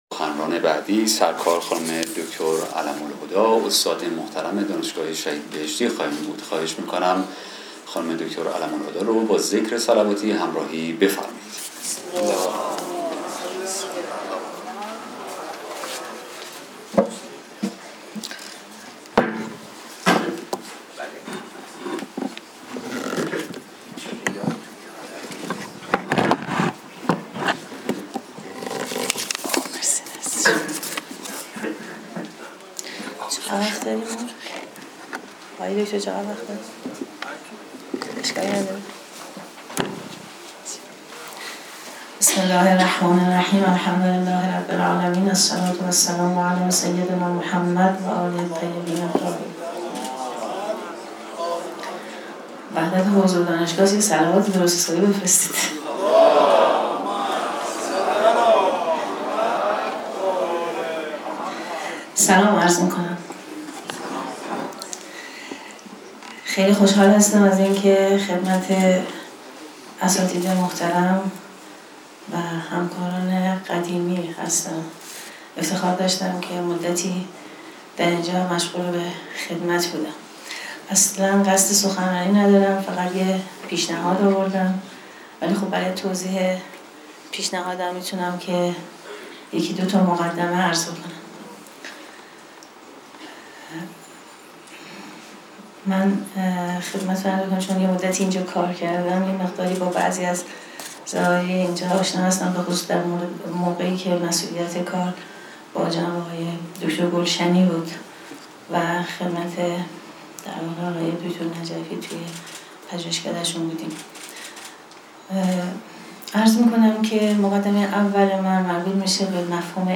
مراسم اختتامیه هفته پژوهشِ پژوهشگاه علوم انسانی و مطالعات فرهنگی، ۲۷ آذرماه ۱۴۰۱ با حضور و سخنرانی شخصیت‌های برجسته و دانشمندان علوم انسانی کشور برگزار شد.